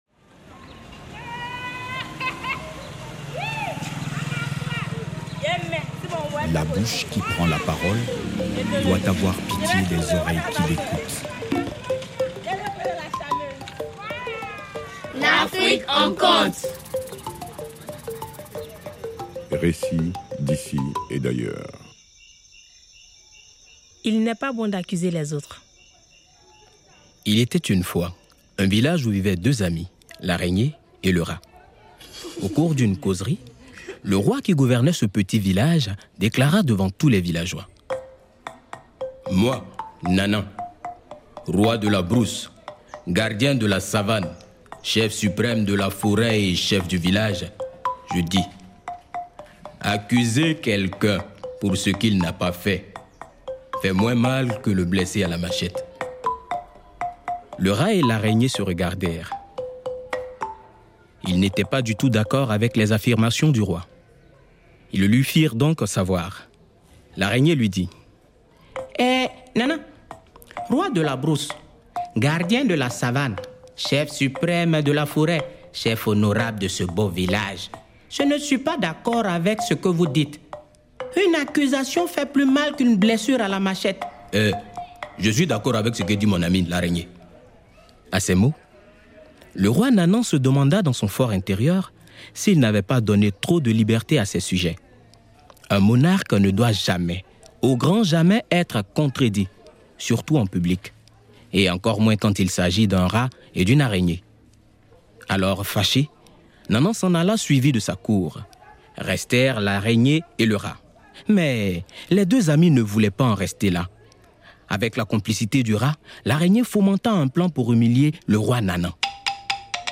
Conte